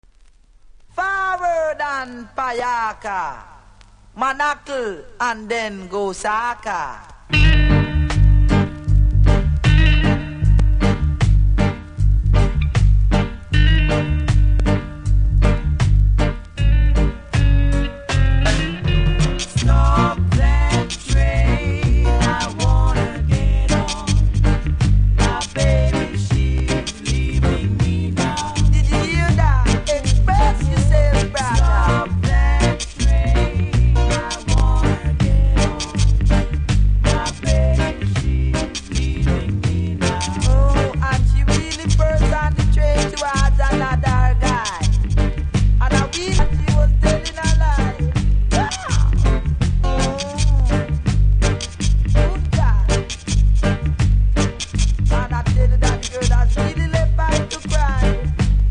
A2針飛びありますので試聴で確認下さい。ジャマイカ盤なので多少プレス起因のノイズはありますがA2以外はOKです。